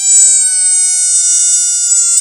Balloon Sample